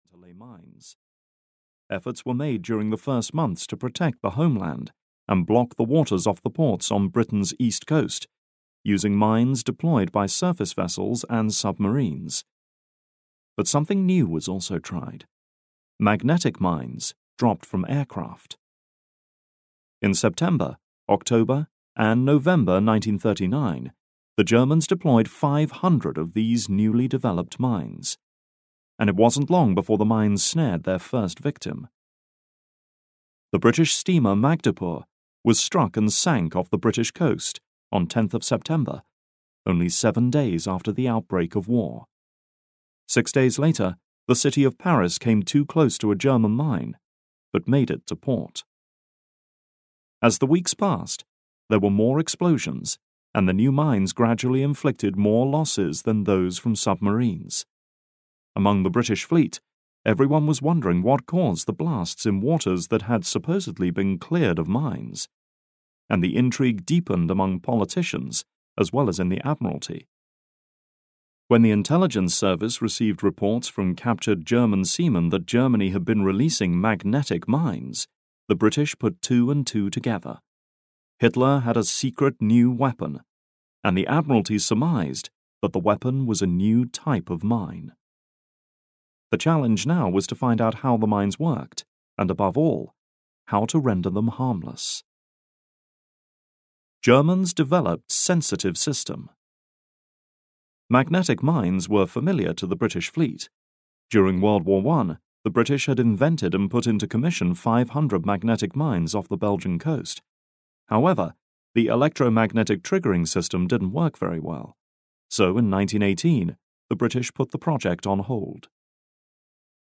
Audio knihaIncredible Weapons (EN)
Ukázka z knihy